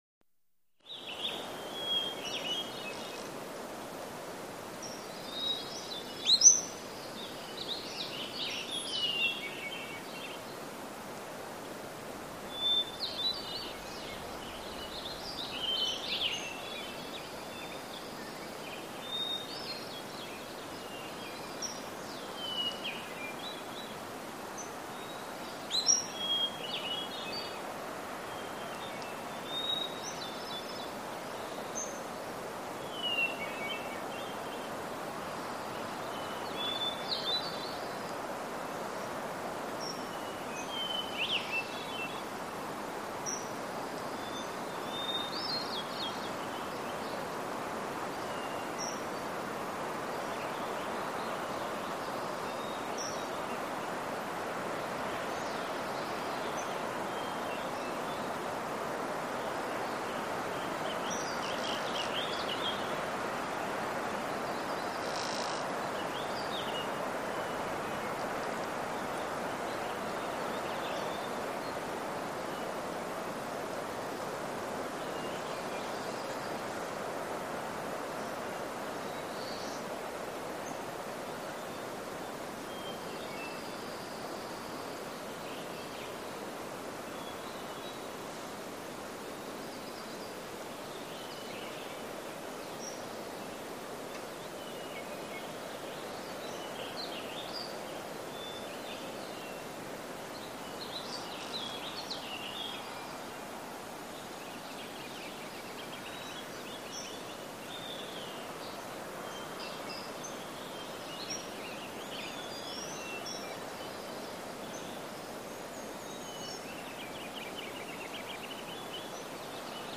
Mountains
Mountain Ambience With Close Perspective Bird Song And Light Wind Gusts.